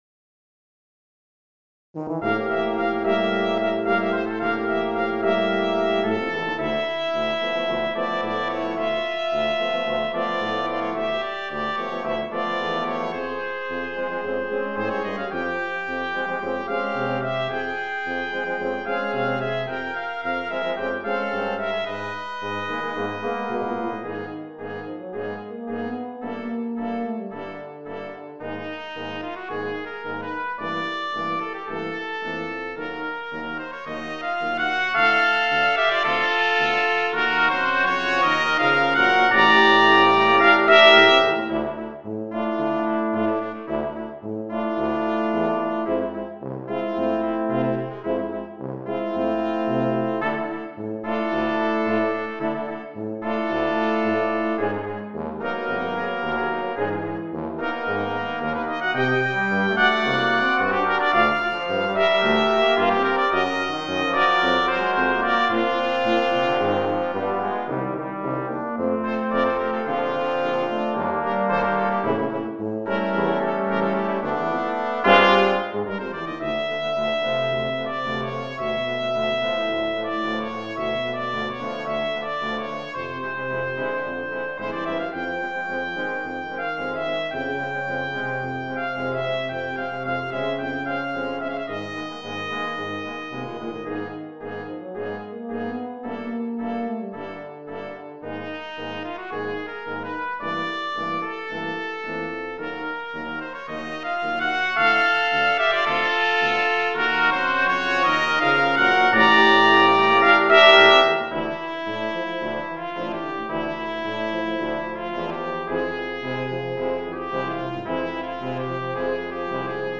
Sextuor de Cuivres